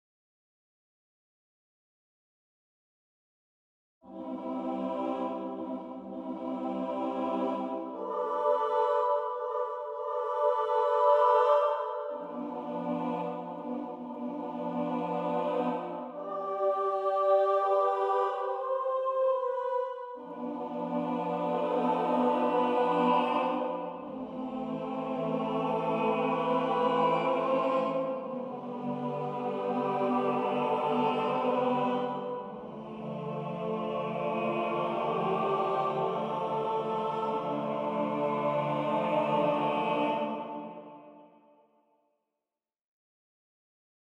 contrast-5-eigener-choir-standard.mp3